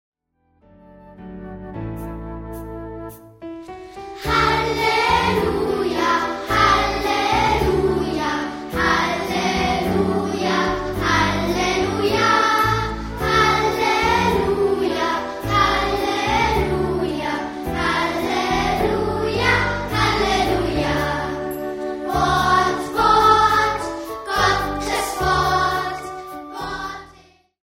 für einstimmigen Kinderchor und Begleitinstrumente